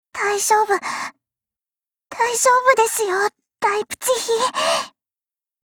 贡献 ） 协议：Copyright，人物： 碧蓝航线:莱比锡语音 您不可以覆盖此文件。